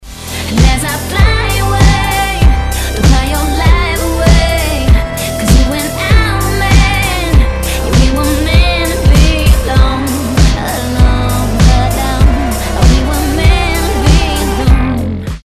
• Качество: 256, Stereo
поп
vocal